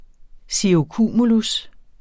Udtale [ siʁoˈkuˀmulus ]